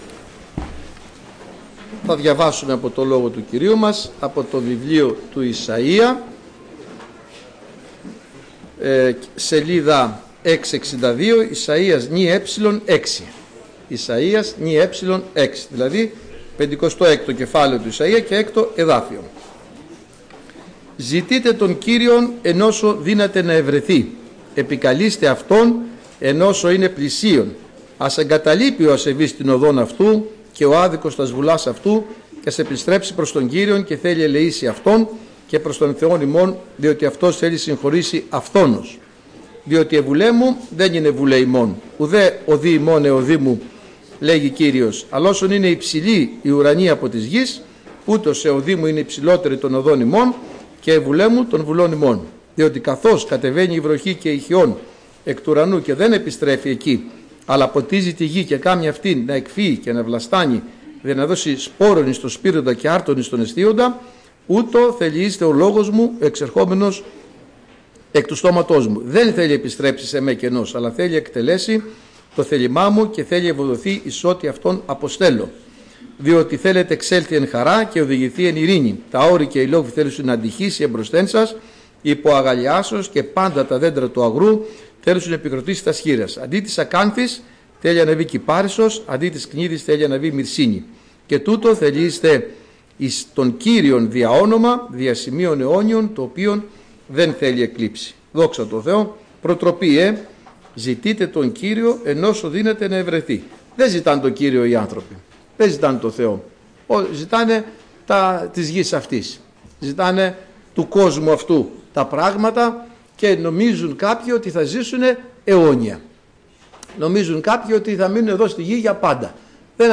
Κήρυγμα Κυριακής